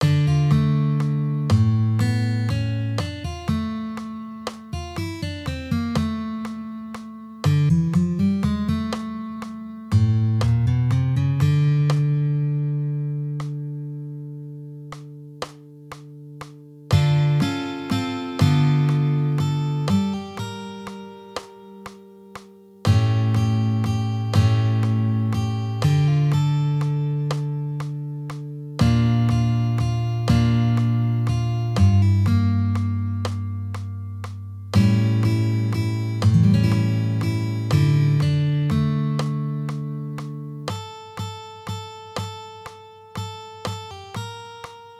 Arrangiamento per chitarra con accordi trasposto in D minore